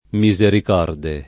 misericorde [ mi @ erik 0 rde ] agg.